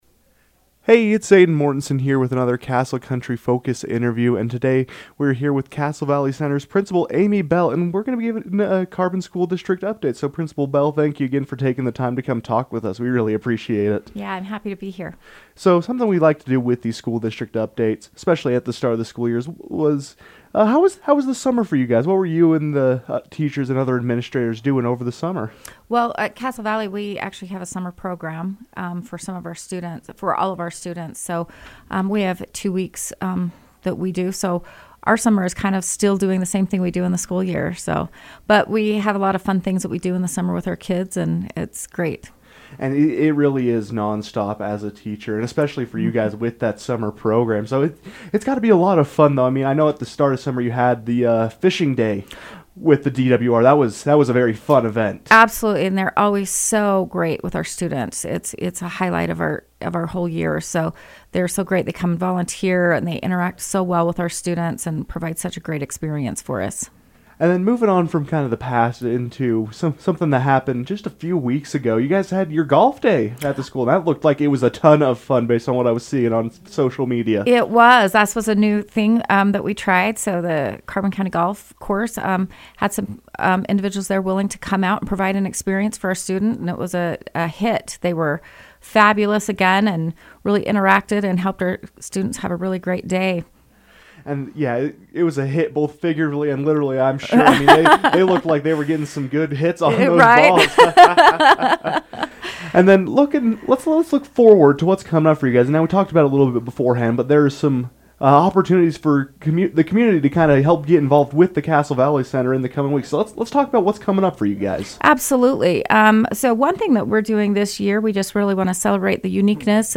Castle Country Radio continues its weekly spotlight on the Carbon School District.